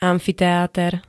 amfiteáter [-t-t-] -tra L -tri pl. N -tre m.
Zvukové nahrávky niektorých slov
5i42-amfiteater.ogg